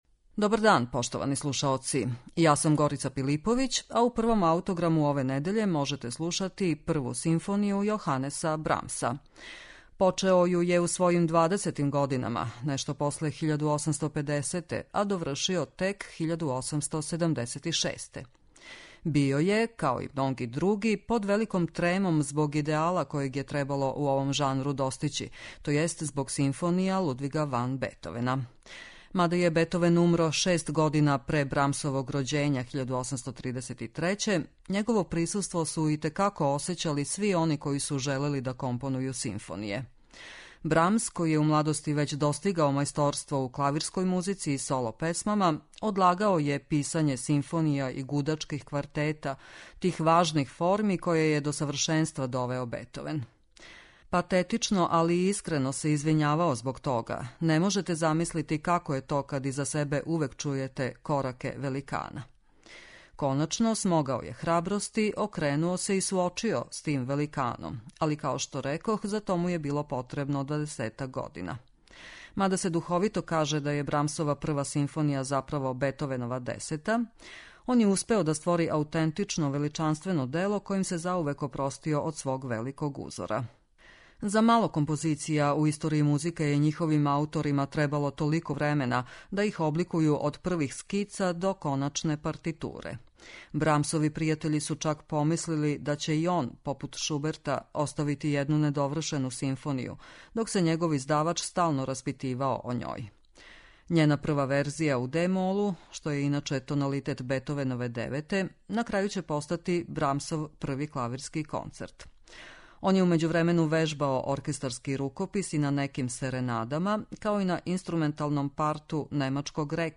ПРВА СИМФОНИЈА ЈОХАНЕСА БРАМСА